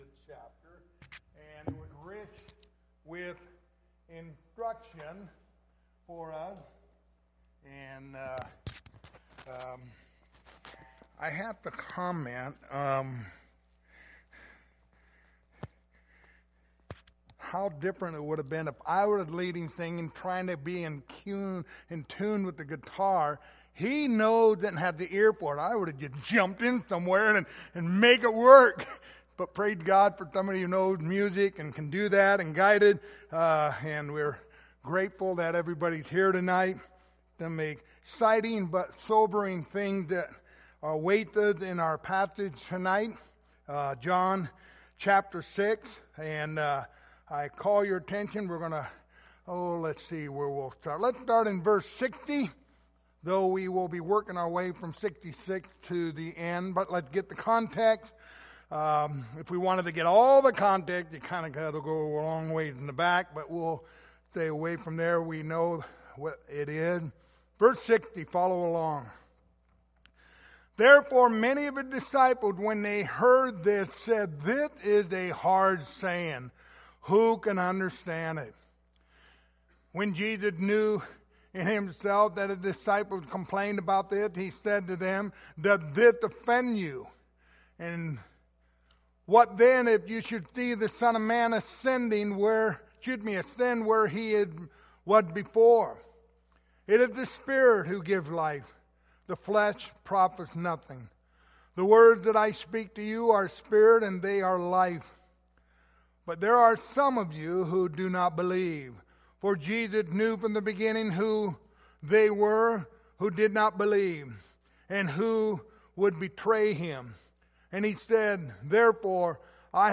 The Gospel of John Passage: John 6:60-71 Service Type: Wednesday Evening Topics